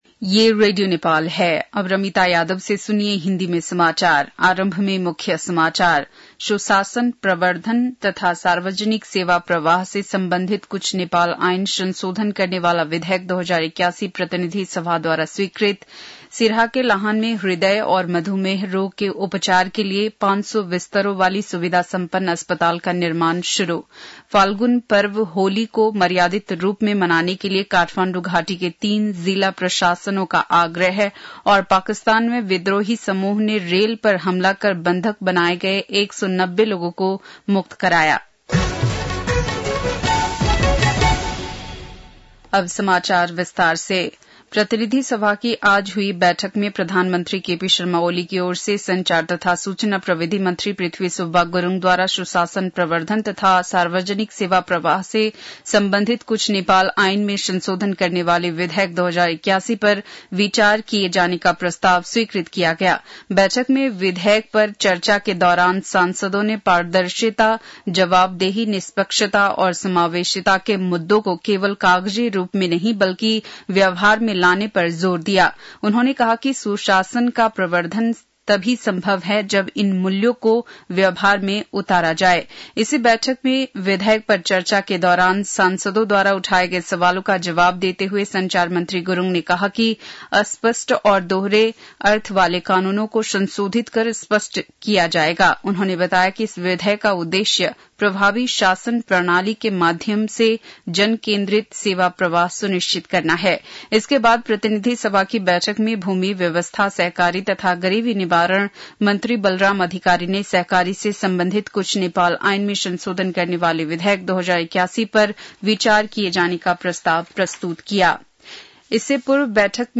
बेलुकी १० बजेको हिन्दी समाचार : २९ फागुन , २०८१